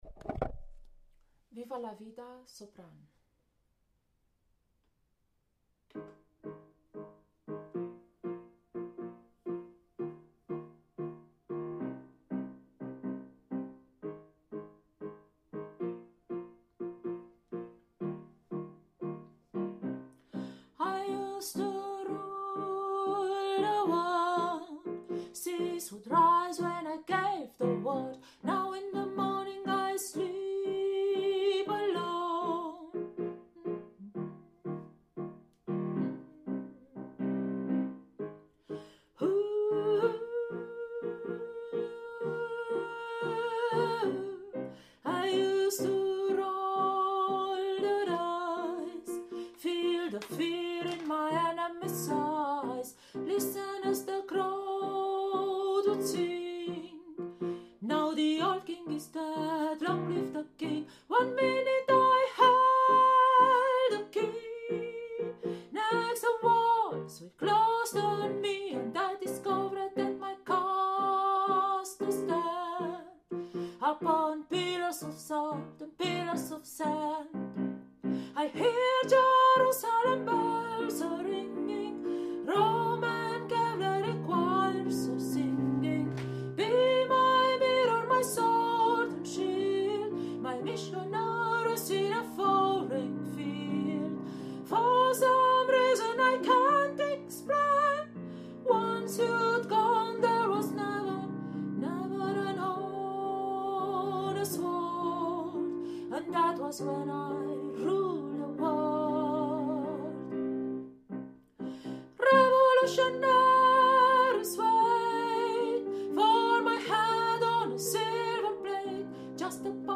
Viva-la-Vida-Sopran.mp3